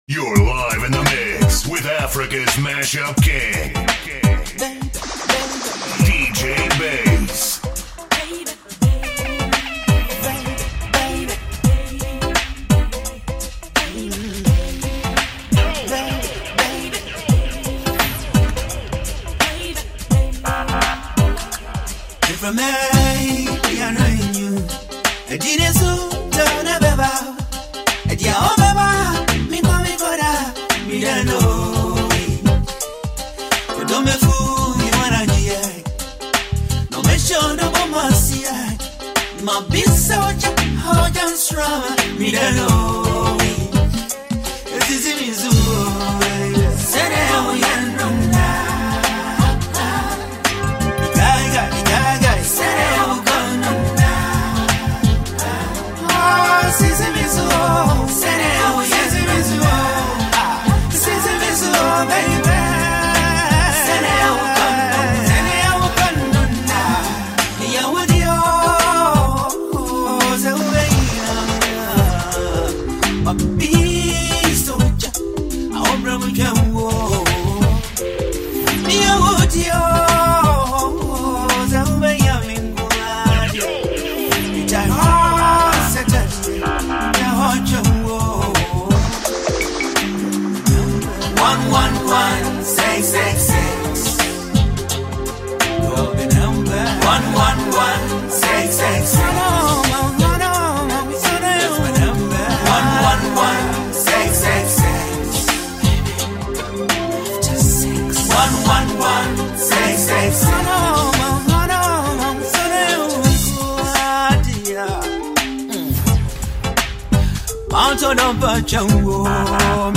highlife
90-minute-long mixtape